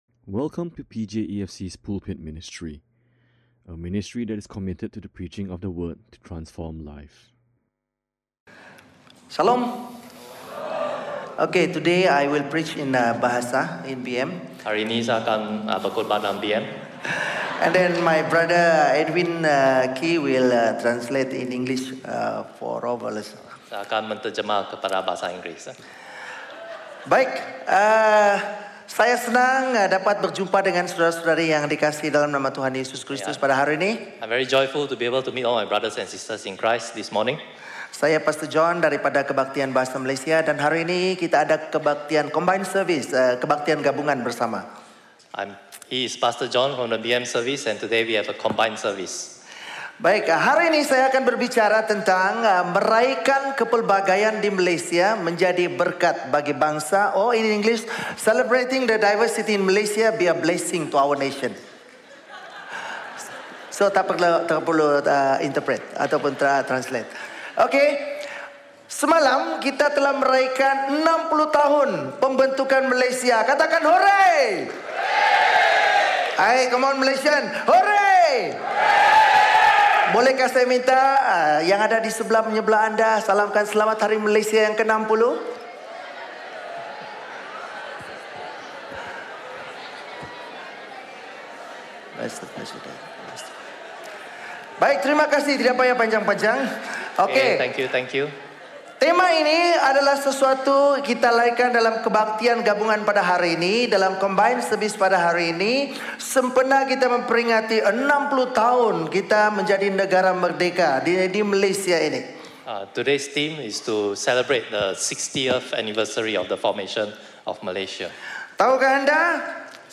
Listen to Sermon Only
In conjunction with Malaysia Day, this is a combined service.